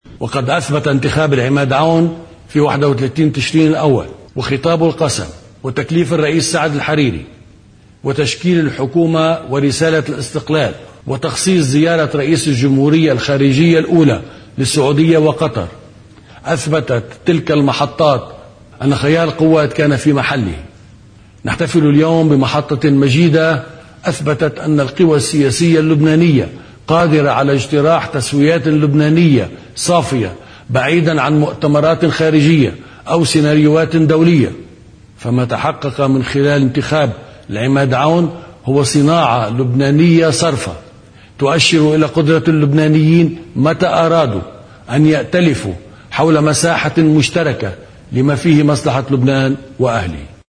جعجع في الذكرى السنوية على التفاهم بين التيار الوطني الحر والقوات اللبنانية: